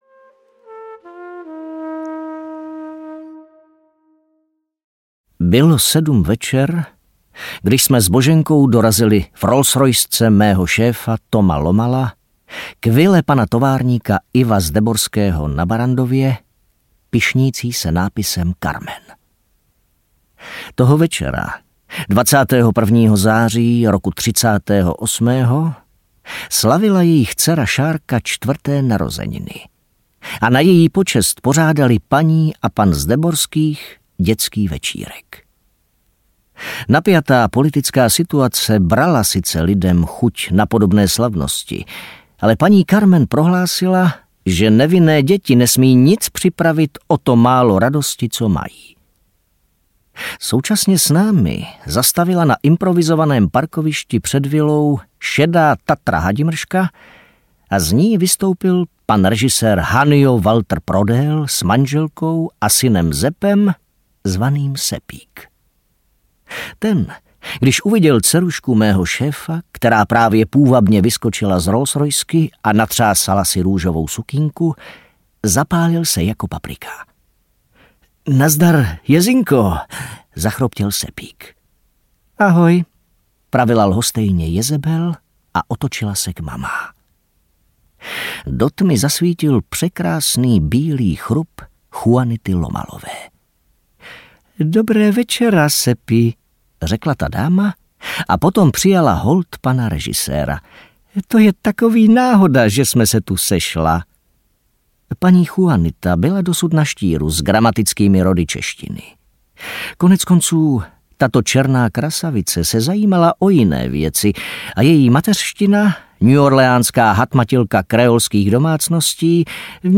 Vražda se zárukou audiokniha
Ukázka z knihy